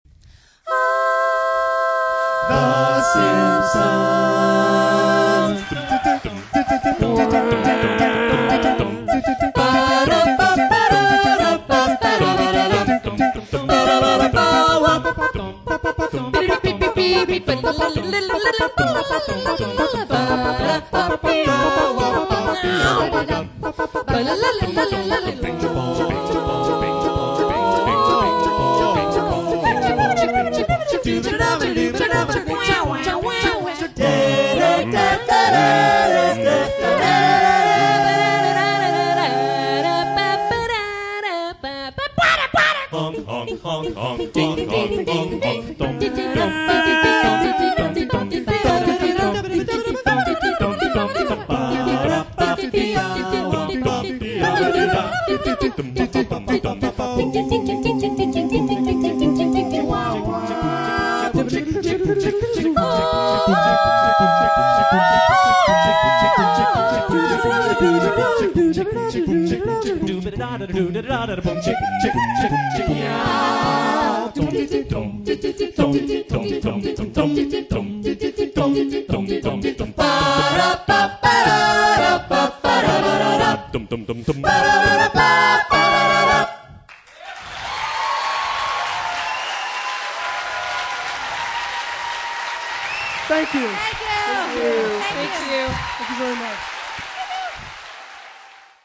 November 11, 2000 at the Seattle Art Museum